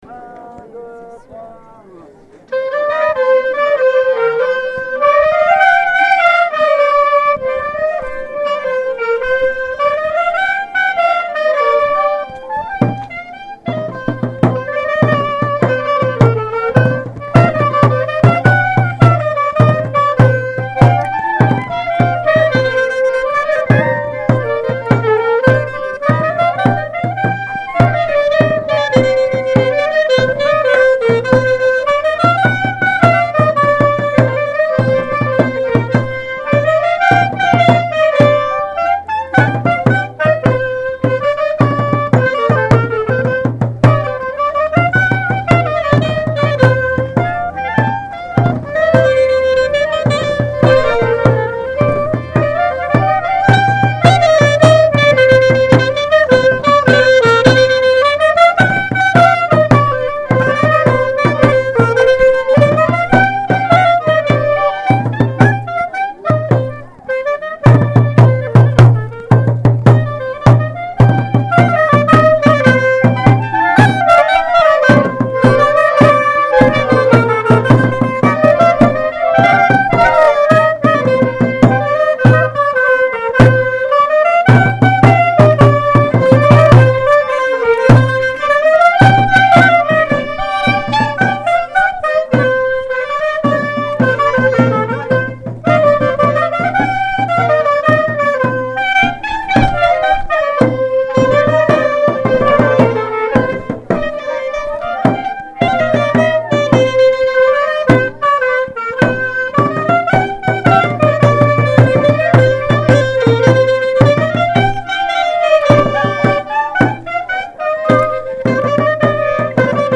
07_marche2-clarinettes.mp3